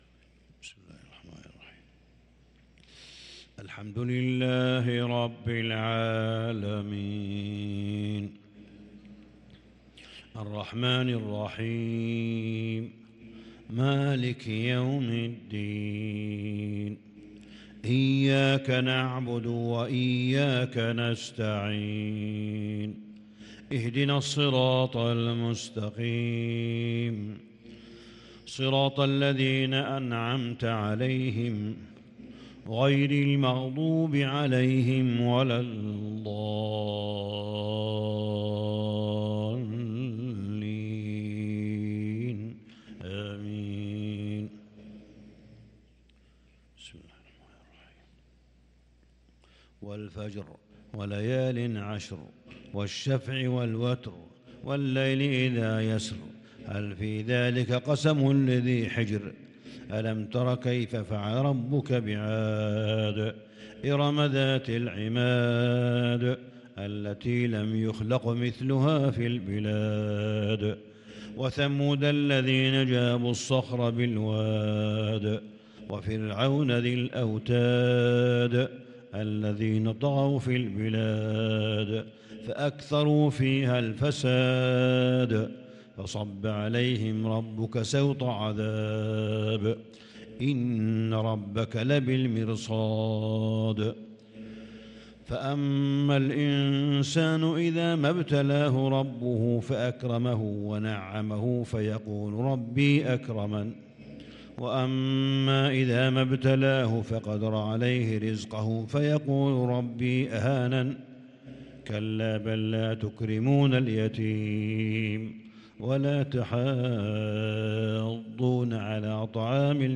فجر الأربعاء 5-9-1443هـ سورتي الفجر و البلد | Fajr prayer from Surat Al-Fajr and Al-Balad 6-4-2022 > 1443 🕋 > الفروض - تلاوات الحرمين